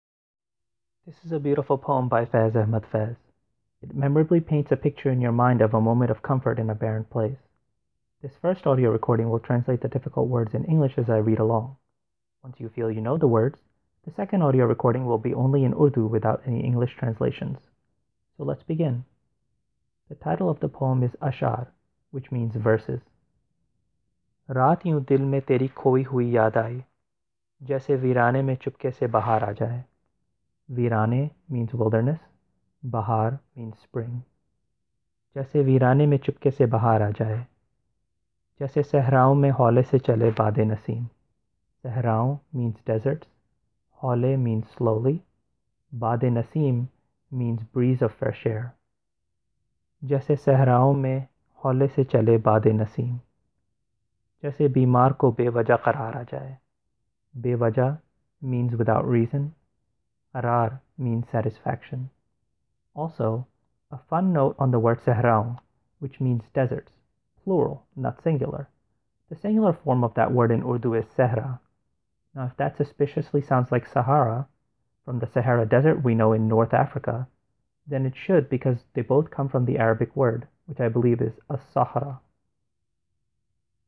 The first audio recording will translate the difficult words in English as I read along in Urdu.